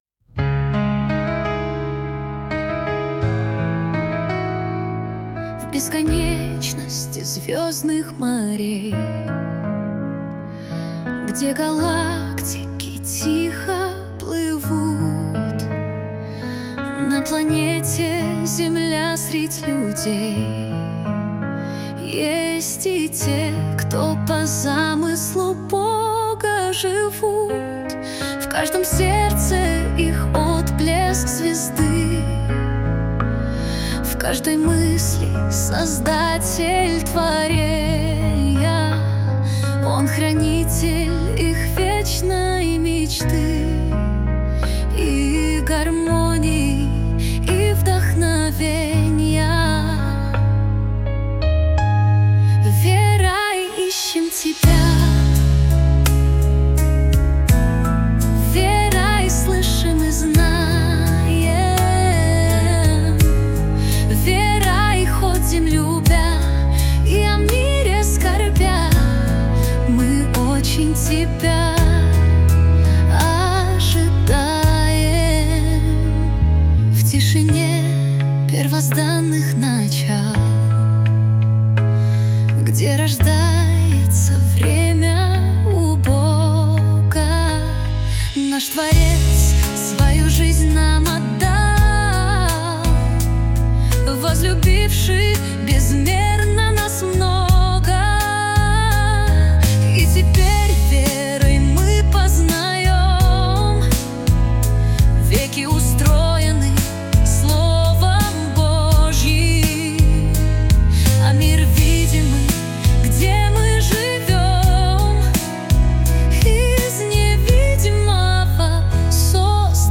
песня ai
197 просмотров 1078 прослушиваний 55 скачиваний BPM: 84